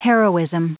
Prononciation du mot heroism en anglais (fichier audio)
heroism.wav